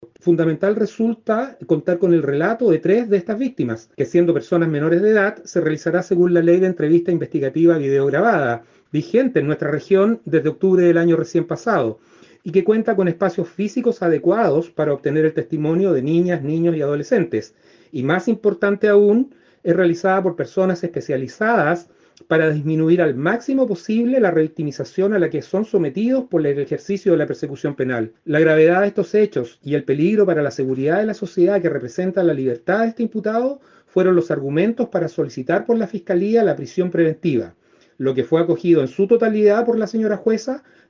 Asimismo, dio cuenta el fiscal que al imputado se le otorgó la cautelar de prisión preventiva.